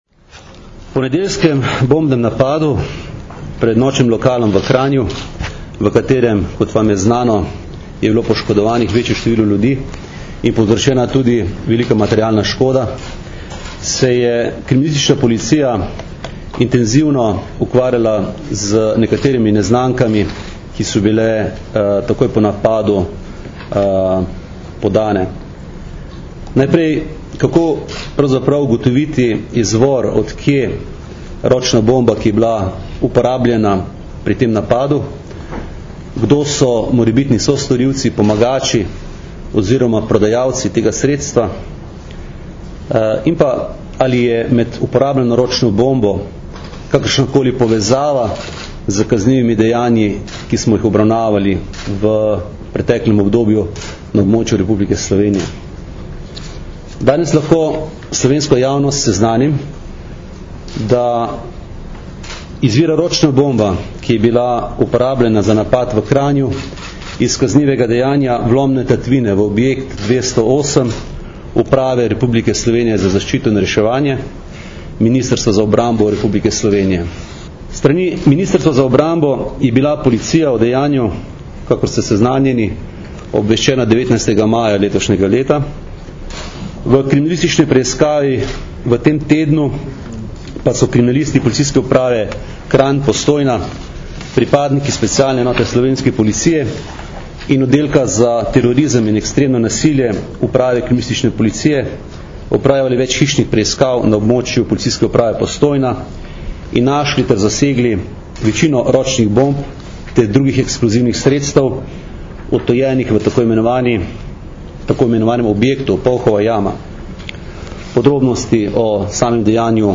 Zvočni posnetek izjave mag. Aleksandra Jevška (mp3)